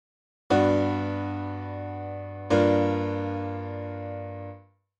▼GM7→Gm7
GM7→Gm7は、ベースがそのまま留まっている定番の美しい進行です。